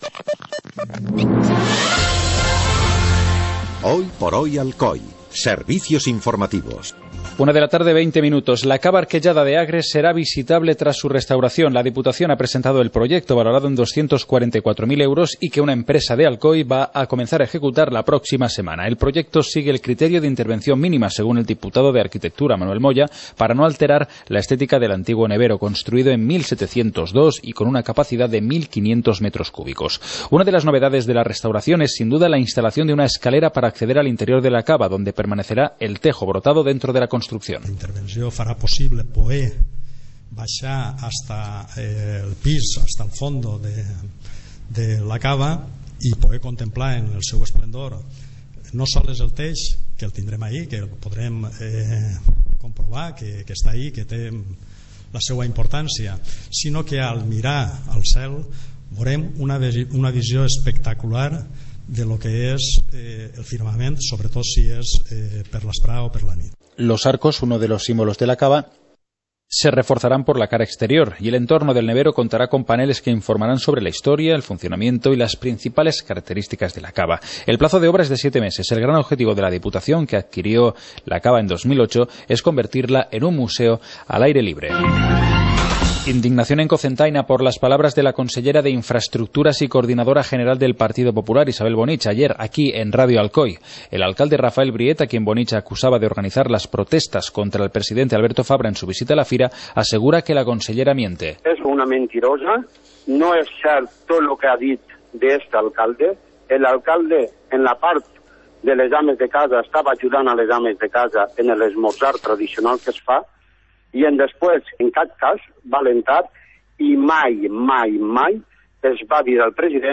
Informativo comarcal - viernes, 07 de noviembre de 2014